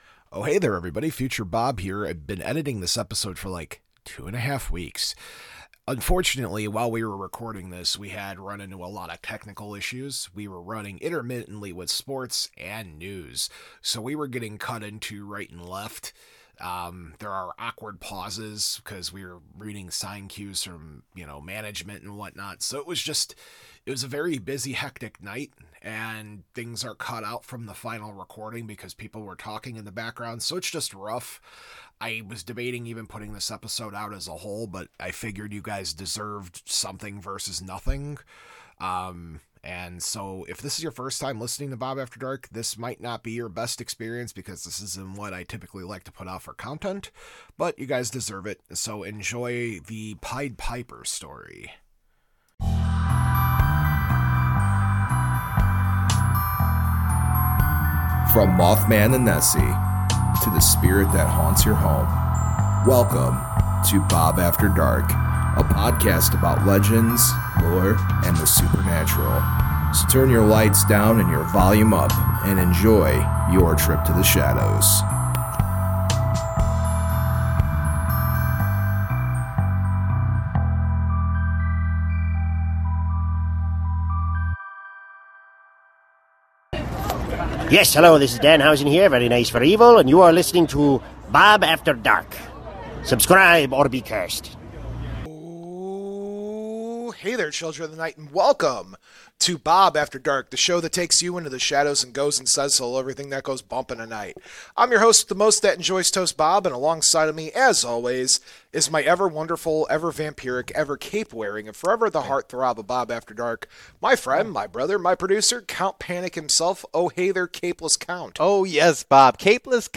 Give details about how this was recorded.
*NOTE* This is more or less a lost episode. It is the best cut we could get.